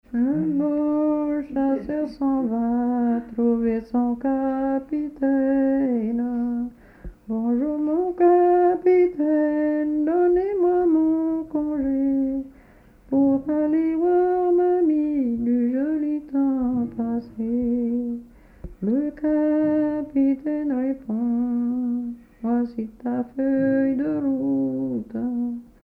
Localisation Bellevaux
Pièce musicale inédite